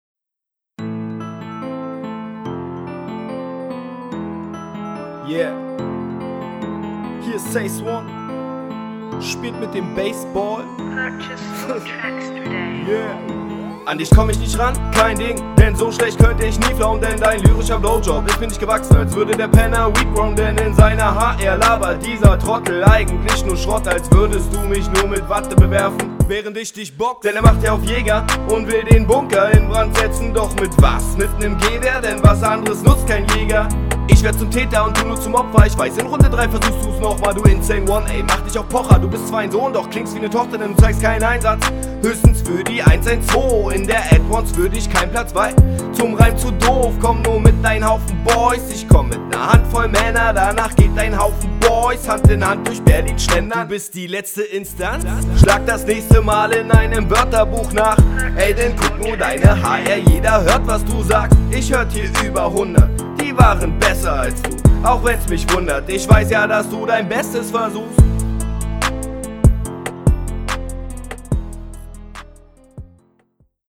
Flow: Deutlich straighter und weniger Melodisch, aber abwechslungsreichere Patterns.
Der Flow ist simpler gestrickt, lenkt dafür aber nicht vom Text ab.